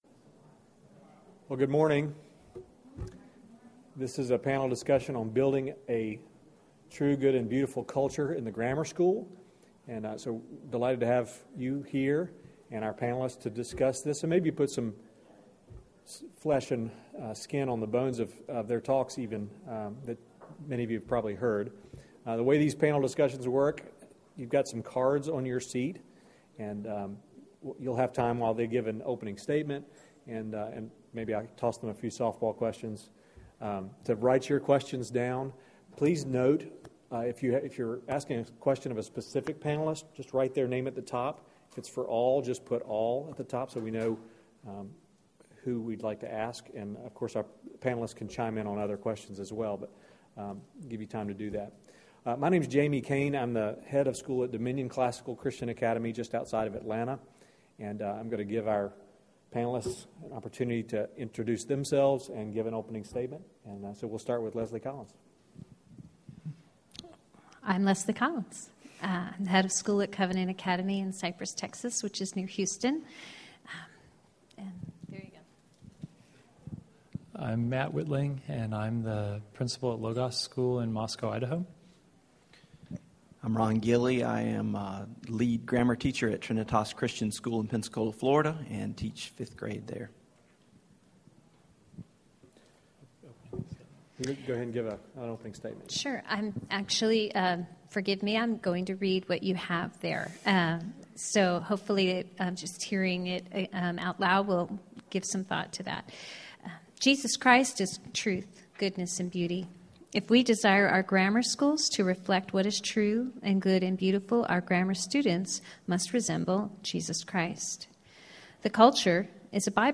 Building Toward a True, Good, and Beautiful Grammar School Culture – Panel Discussion | ACCS Member Resource Center
2014 Workshop Talk | 0:53:05 | K-6, Culture & Faith, General Classroom